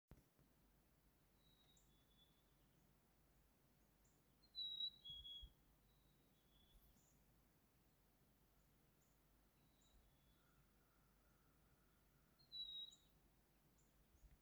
The Cardinals told me. Not the best audio, I was naked by the door at 6 AM. Turn up the sound.
Oh and I got such a kick out of the image of you standing naked at the front door recording a cardinal song!!
Cardinal.mp3